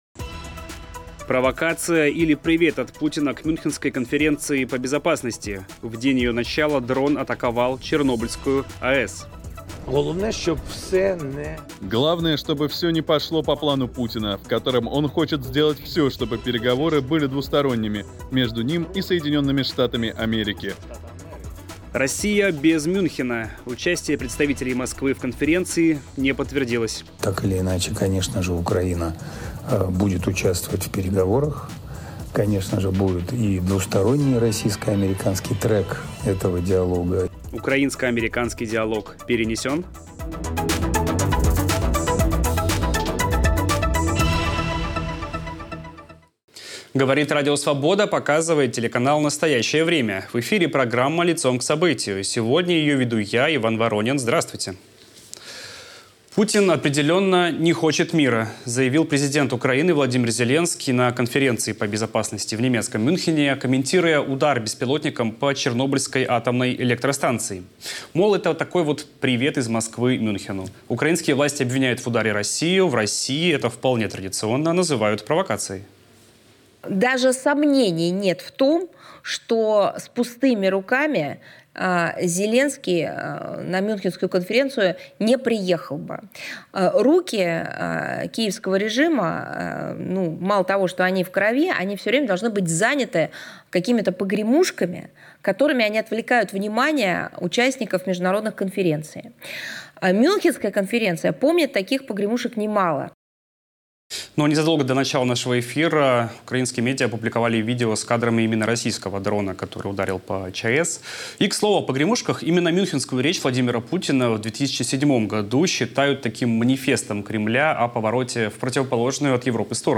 О том, чего от встреч украинского и американского руководства ждут в России и о том, какие выводы из происходящего делает российское антивоенное движение, говорим с одним из приглашённых гостей конференции, российским оппозиционным политиком Андреем Пивоваровым.